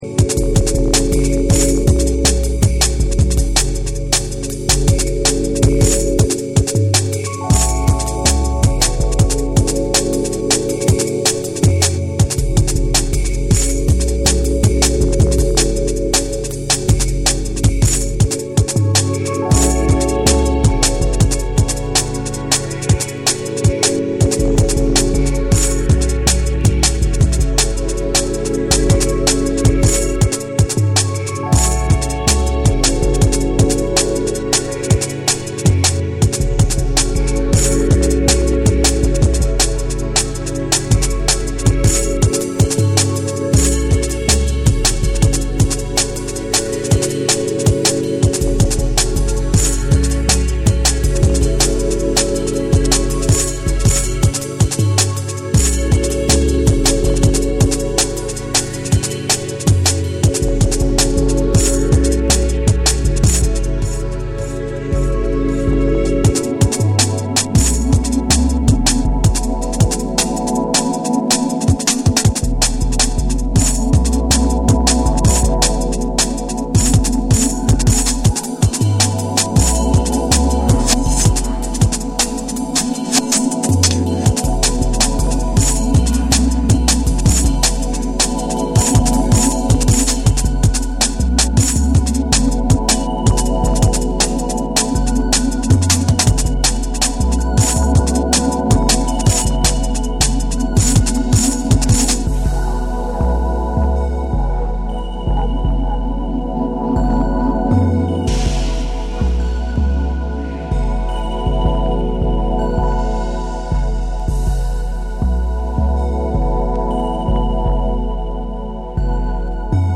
精密なブレイクビーツの奥に広がるアンビエンスが印象的なドラムンベース
」は、硬質でありながら、深い空間処理と浮遊感のあるテクスチャーが包み込み、静かな緊張感を生む。
JUNGLE & DRUM'N BASS / BREAKBEATS